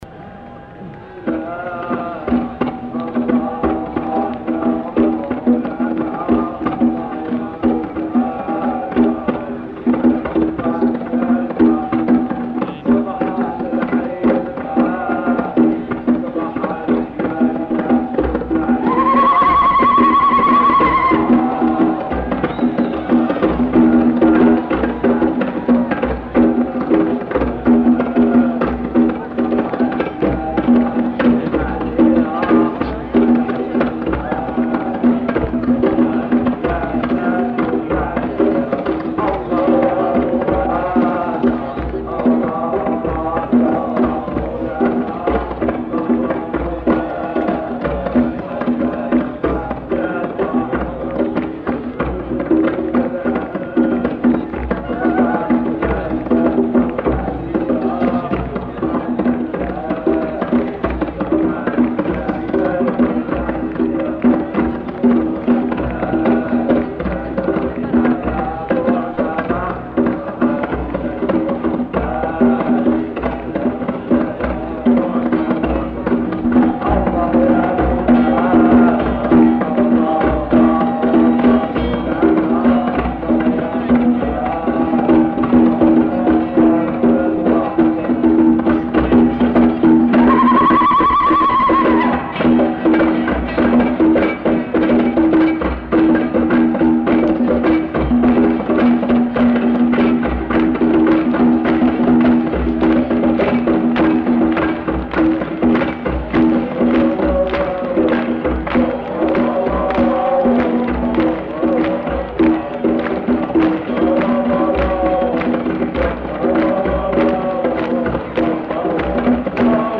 Chant (non identifié)
Lieu : [sans lieu] ; Aveyron
Genre : chanson-musique
Type de voix : voix mixtes
Production du son : chanté
Instrument de musique : percussions